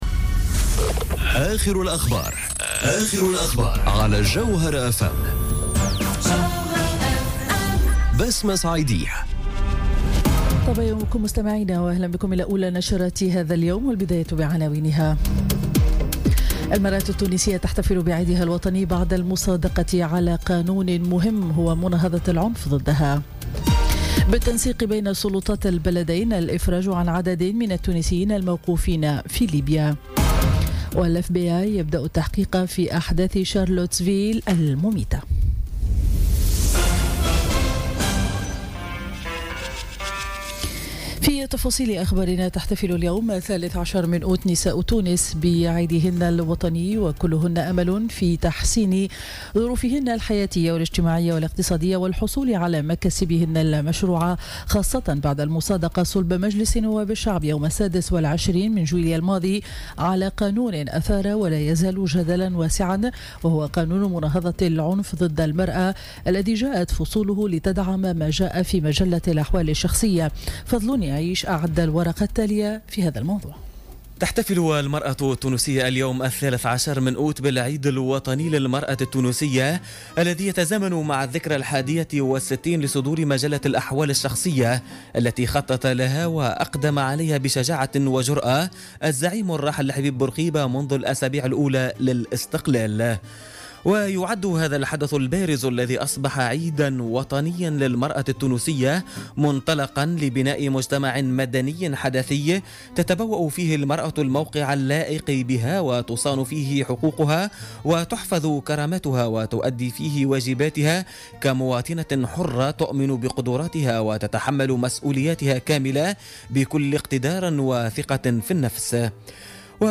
نشرة أخبار السابعة صباحا ليوم الأحد 13 أوت 2017